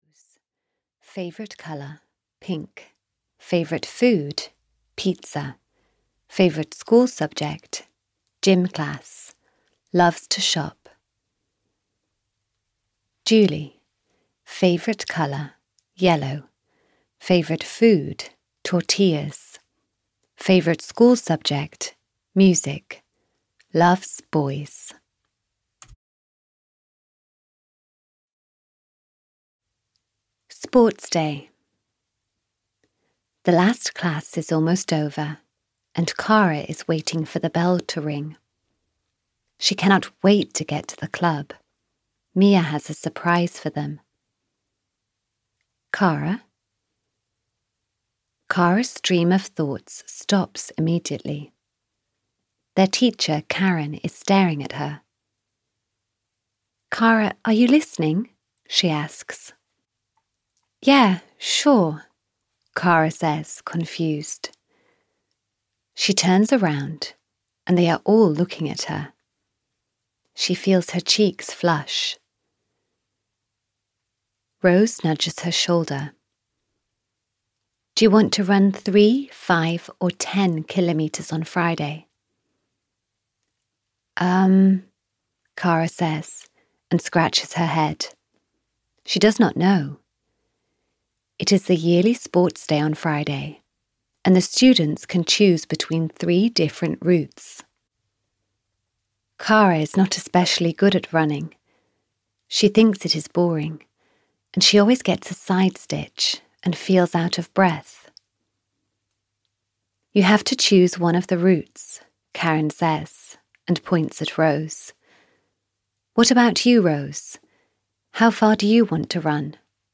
Audio knihaK for Kara 16 - Totally Perfect (EN)
Ukázka z knihy